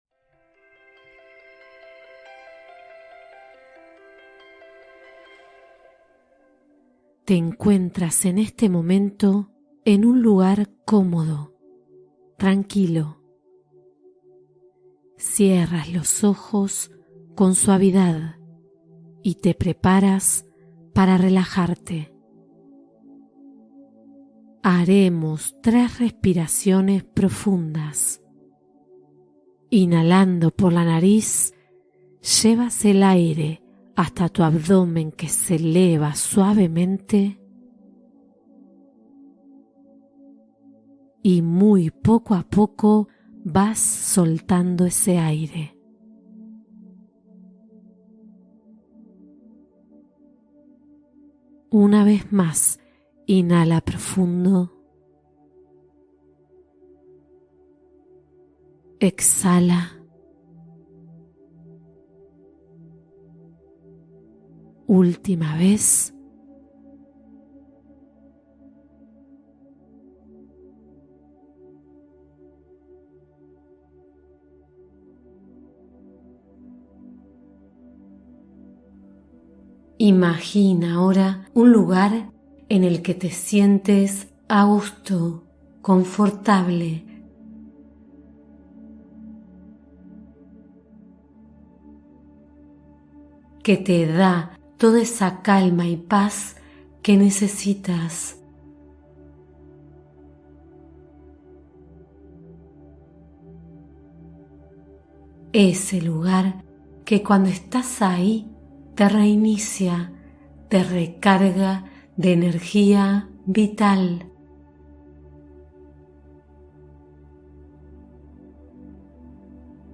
Meditación Corta Energizante: Comienza el Día en Positivo